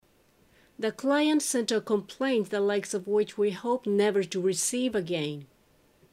ナチュラルスピード：